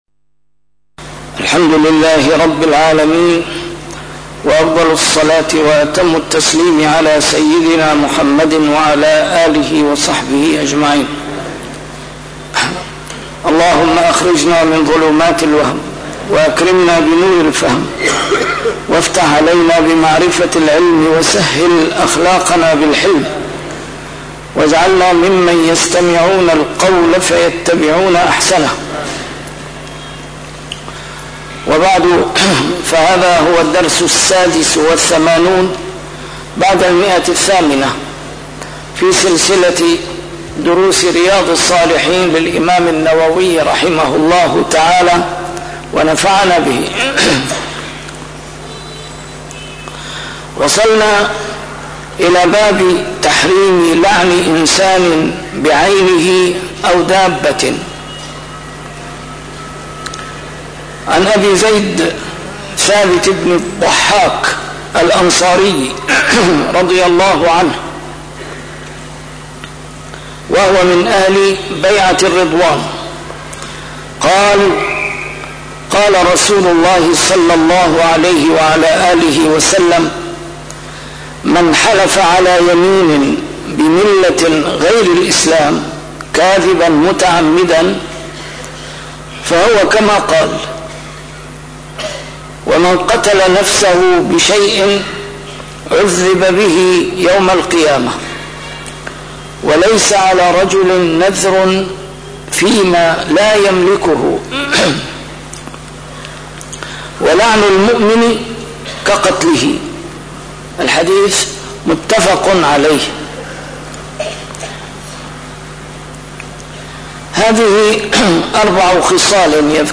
A MARTYR SCHOLAR: IMAM MUHAMMAD SAEED RAMADAN AL-BOUTI - الدروس العلمية - شرح كتاب رياض الصالحين - 886- شرح كتاب رياض الصالحين: تحريم لعن إنسان بعينه أو دابة